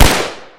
Baby Laughing Meme Sound Effect Button | Soundboard Unblocked